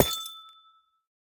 Minecraft Version Minecraft Version latest Latest Release | Latest Snapshot latest / assets / minecraft / sounds / block / amethyst / break2.ogg Compare With Compare With Latest Release | Latest Snapshot